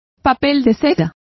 Complete with pronunciation of the translation of tissues.